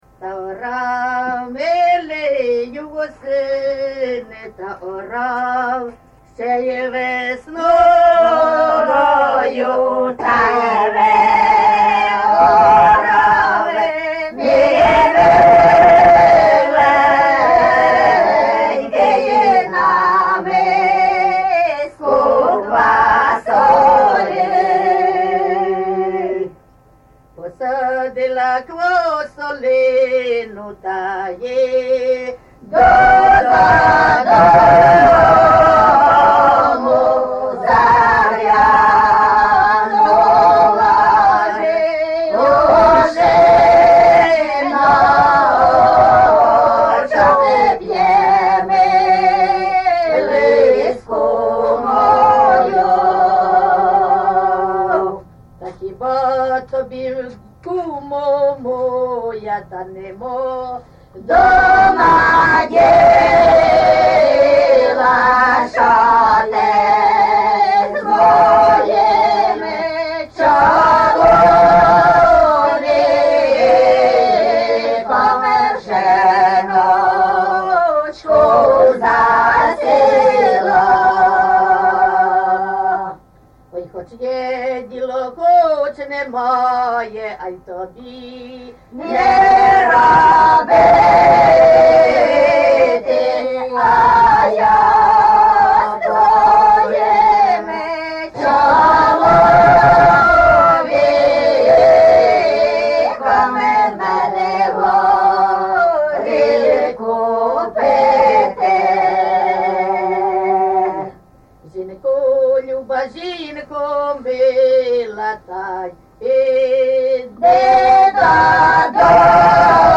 ЖанрПісні з особистого та родинного життя
Місце записус. Лука, Лохвицький (Миргородський) район, Полтавська обл., Україна, Полтавщина